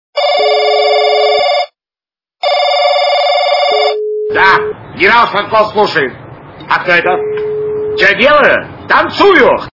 При прослушивании т/с Солдати - Генерал Шматко слушает качество понижено и присутствуют гудки.